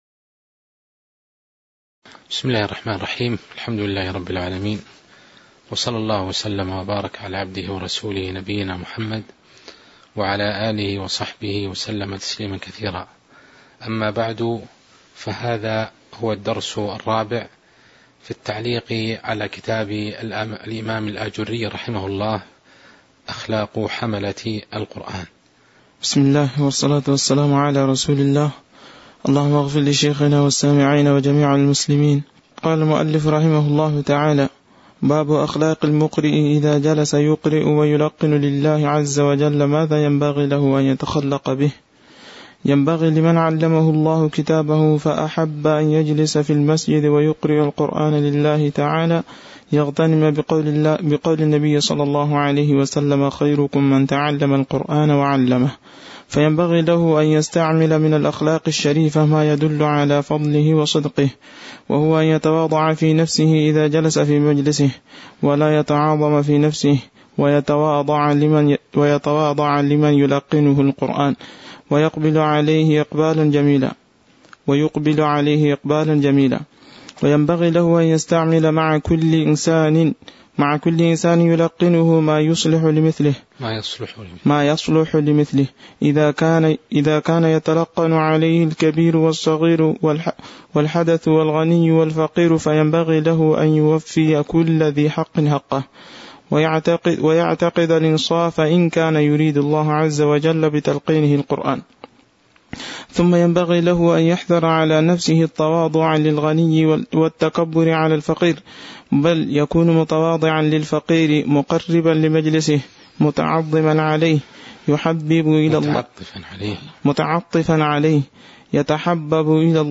تاريخ النشر ٢٦ صفر ١٤٤٢ هـ المكان: المسجد النبوي الشيخ